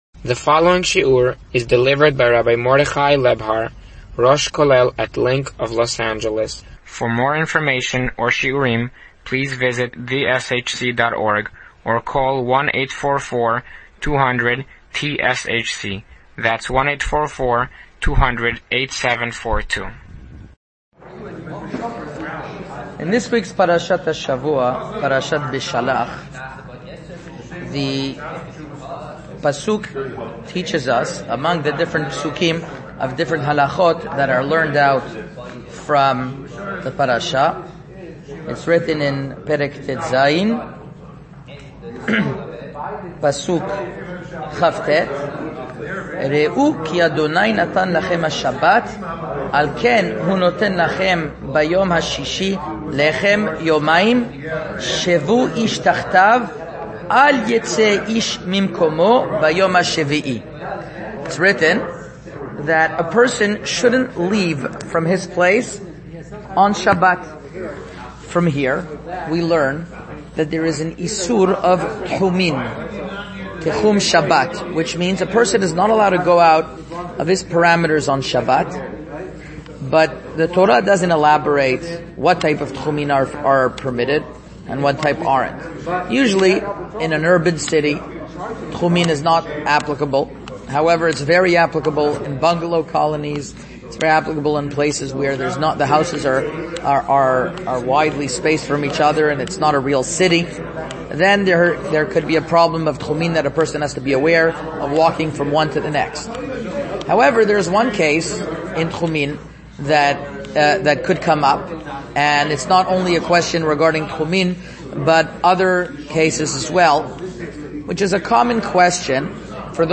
Shiur Halacha